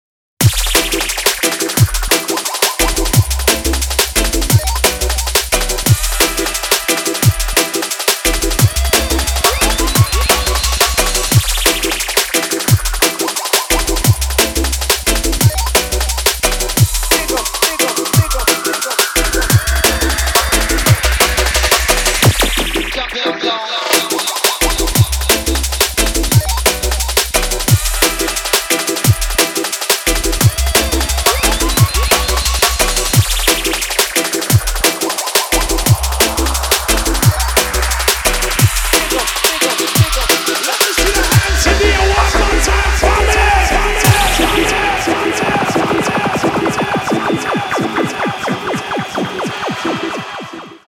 • Качество: 320, Stereo
мужской голос
громкие
dance
Electronic
электронная музыка
drum n bass